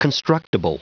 Prononciation du mot constructible en anglais (fichier audio)
Prononciation du mot : constructible